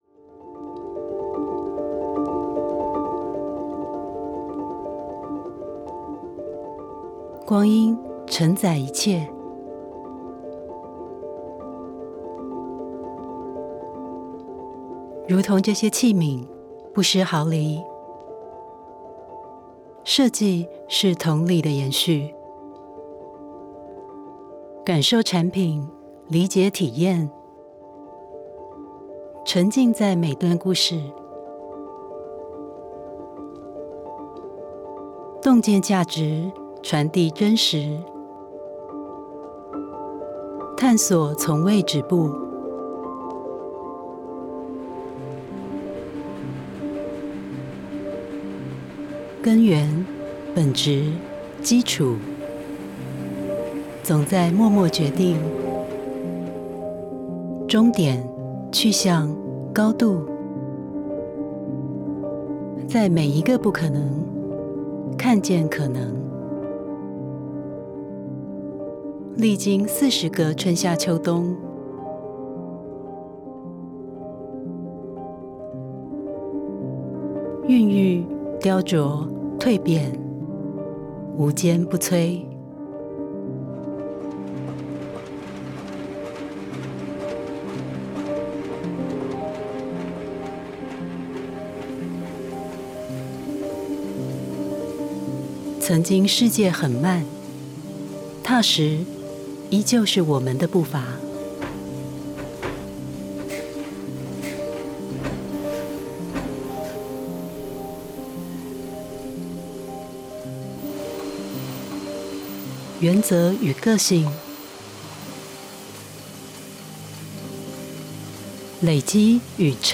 國語配音 女性配音員
✔ 聲音具有親和力，極具穿透力與辨識度